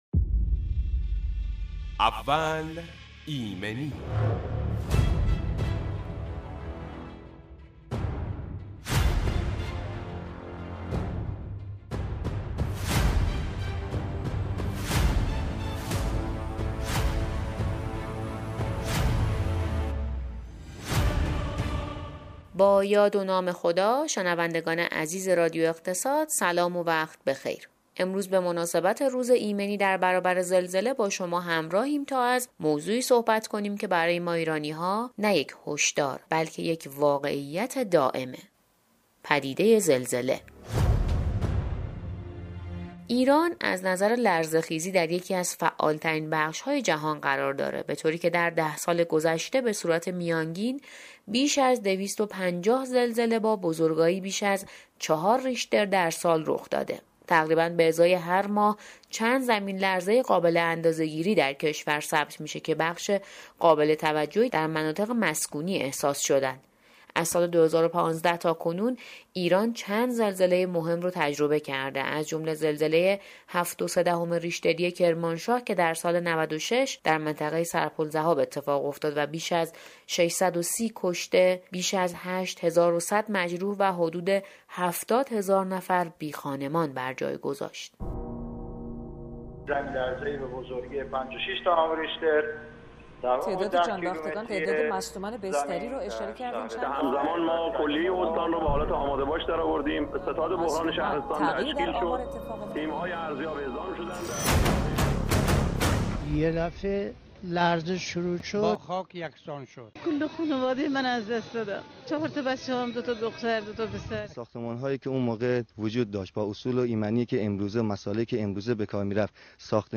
بمناسبت 5 دی سالروز زلزله در بم، ویژه برنامه اول ایمنی برای تحلیل ایمنی در برابر زلزله در رادیو اقتصاد پخش گردید و نکات ارزشمند به مردم عزیز منتقل شد
در دقایق آینده با دو استاد دانشگاه همراه خواهیم بود تا درباره علت افزایش خسارت‌ها، نقش نهادها و وظایف ما برای کاهش تلفات و همچنین رفتار درست هنگام زلزله صحبت کنیم.